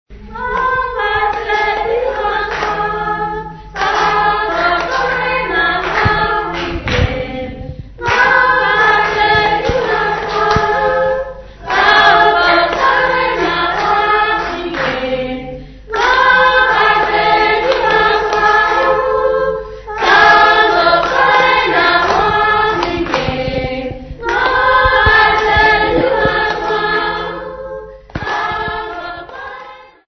Folk music
Field recordings
Traditional Damara lullaby with clapping accompaniment.
96000Hz 24Bit Stereo